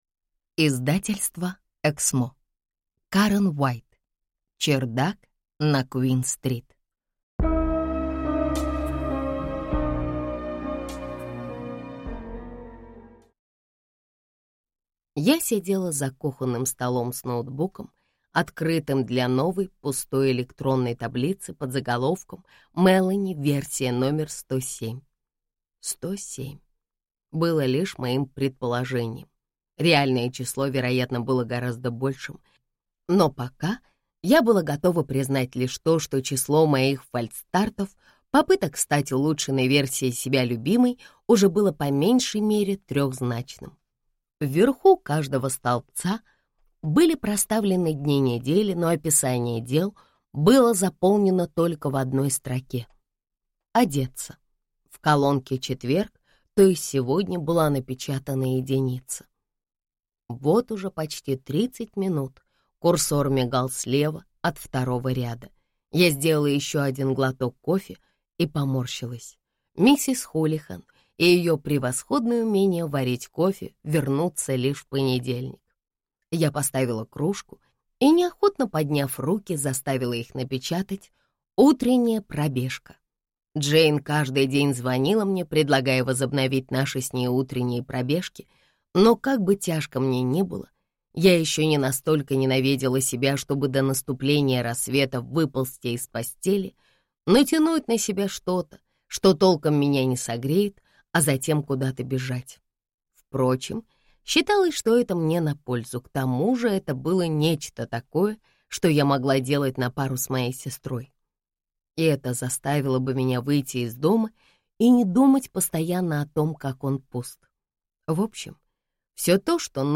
Аудиокнига Чердак на Куин-стрит | Библиотека аудиокниг